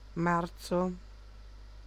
Ääntäminen
Ääntäminen France: IPA: [maʁs] Tuntematon aksentti: IPA: /maʁ/ Haettu sana löytyi näillä lähdekielillä: ranska Käännös Ääninäyte Substantiivit 1. marzo {m} Suku: m .